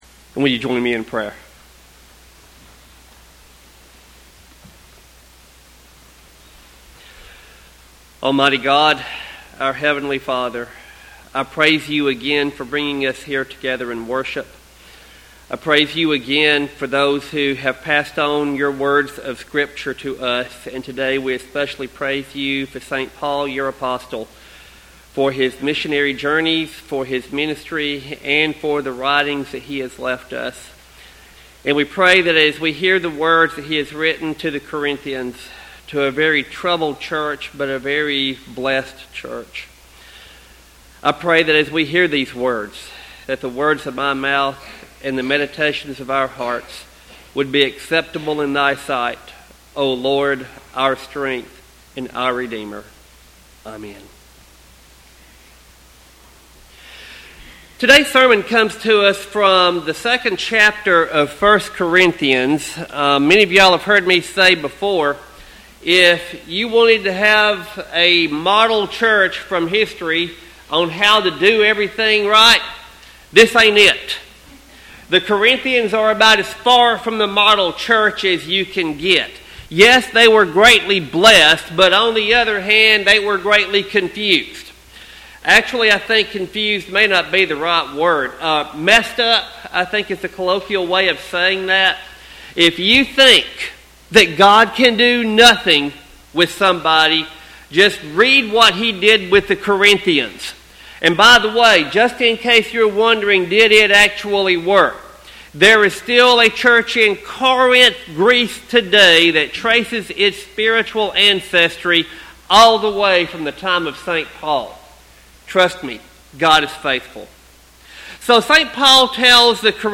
Sermon text: 1 Corinthians 2:1-12.